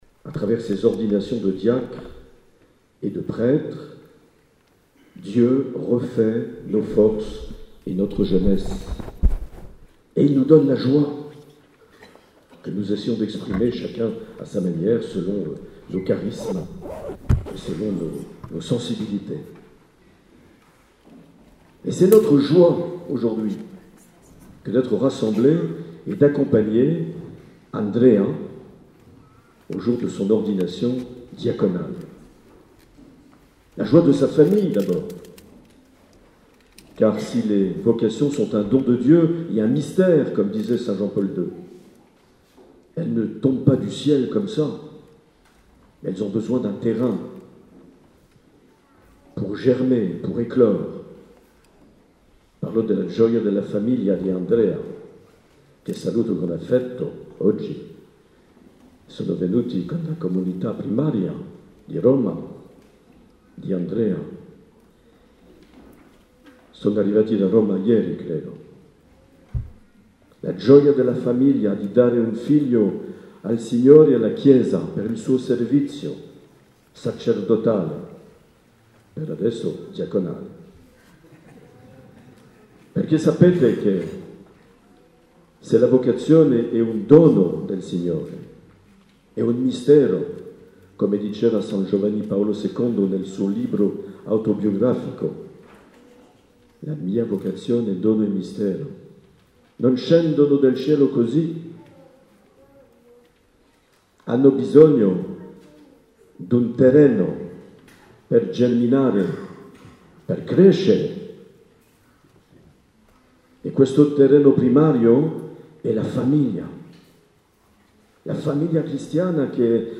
Accueil \ Emissions \ Vie de l’Eglise \ Evêque \ Les Homélies \ 4 mai 2019
Une émission présentée par Monseigneur Marc Aillet